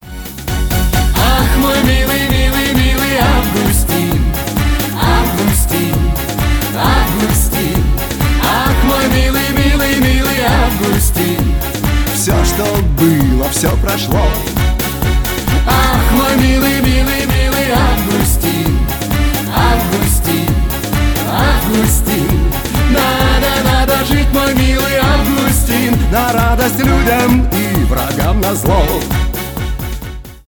танцевальные
поп , зажигательные